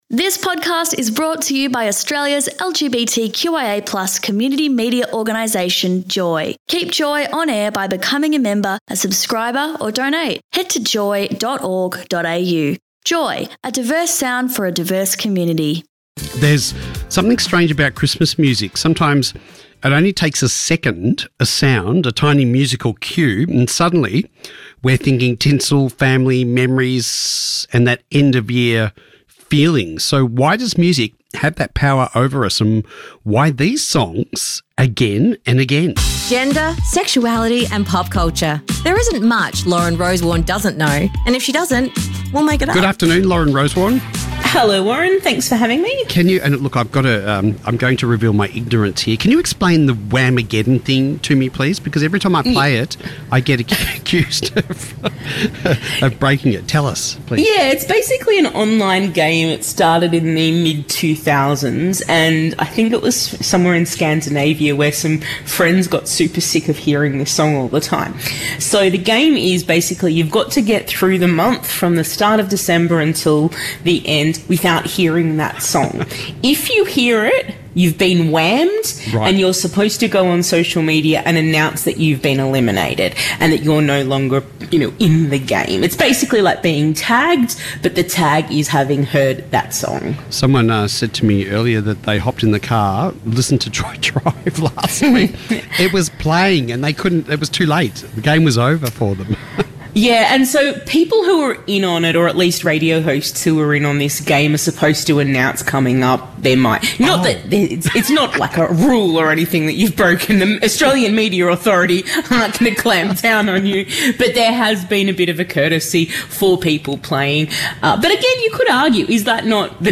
The Melbourne Rainbow Band join us for a live acoustic session in the JOY Drive studio as part of Radiothon 2023.